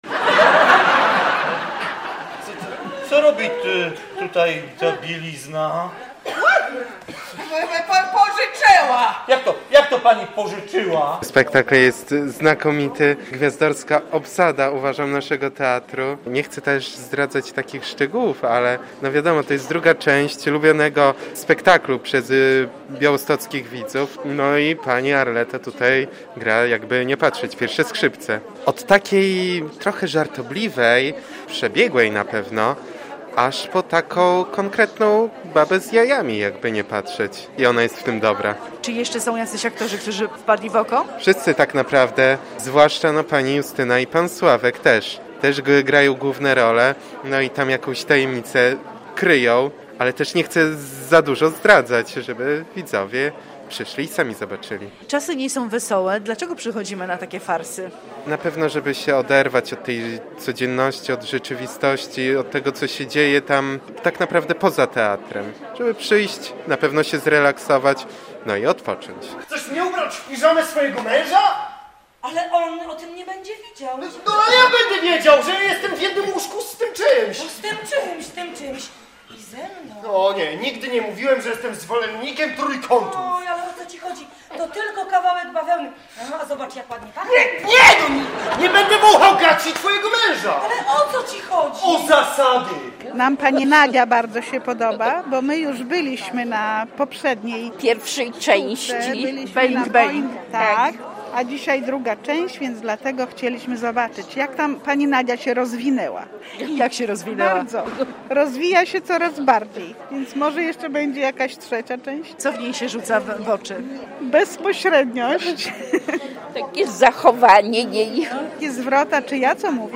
Wrażenia widzów po spektaklu "Pomoc domowa"